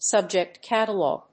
アクセントsúbject càtalog